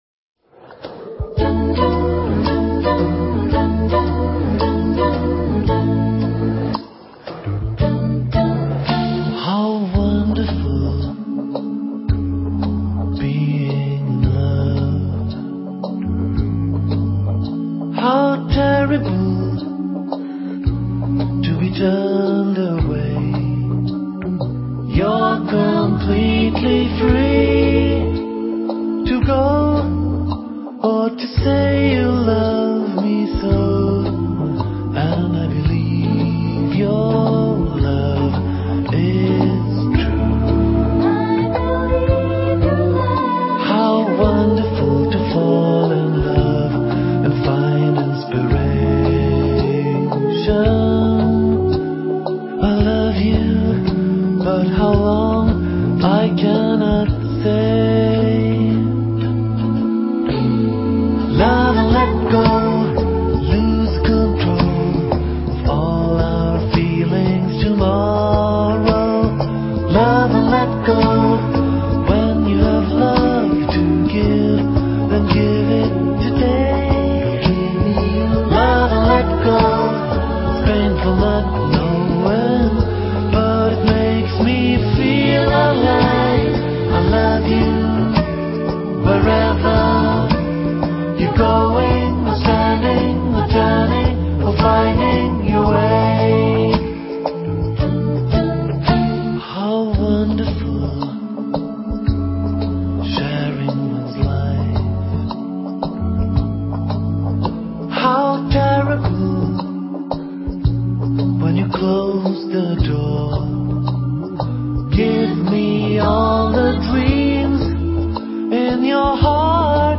Vocal jazz